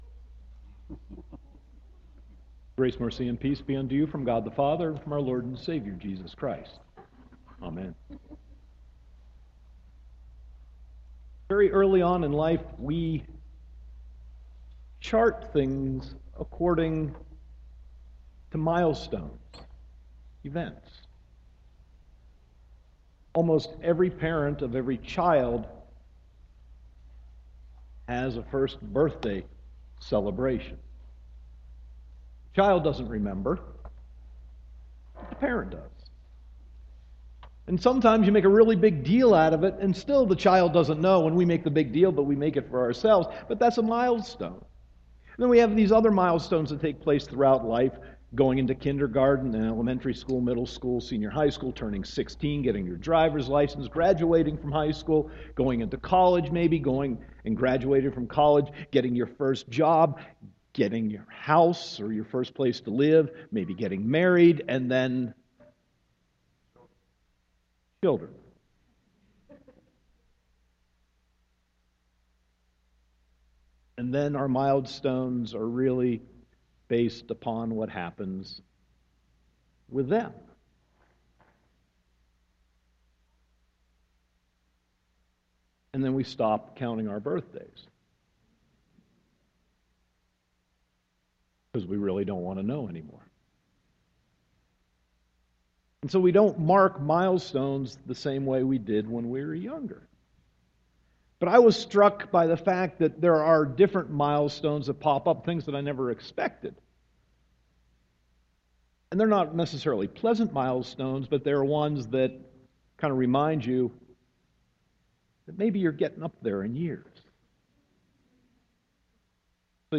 Sermon 6.28.2015